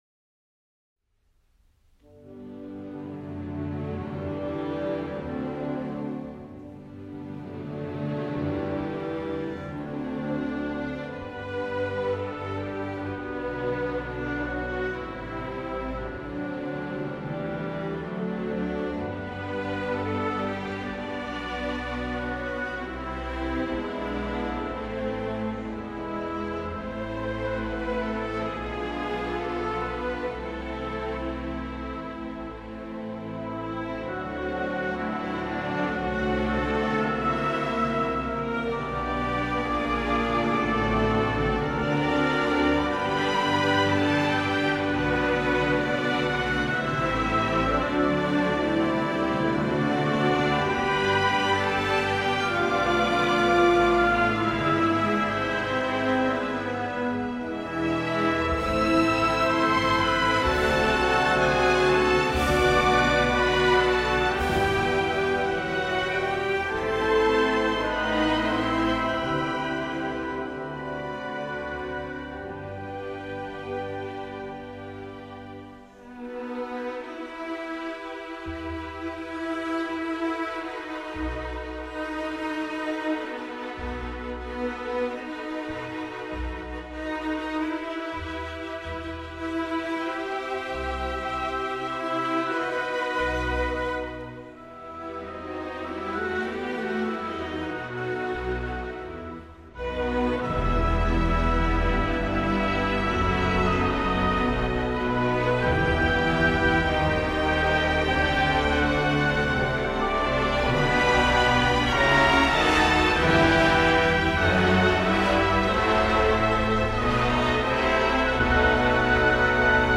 Sanctuary of the Heart for orchestra